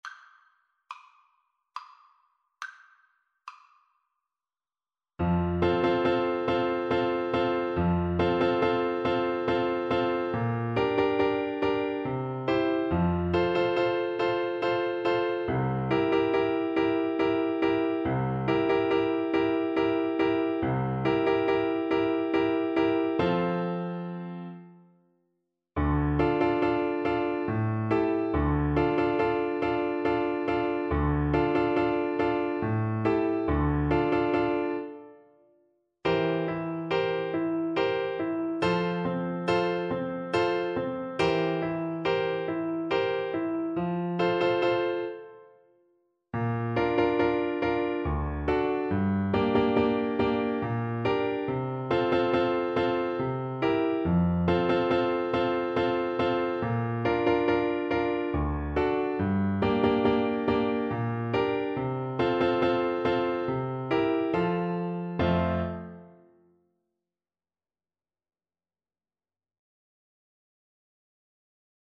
Clarinet
3/4 (View more 3/4 Music)
Maestoso
Traditional (View more Traditional Clarinet Music)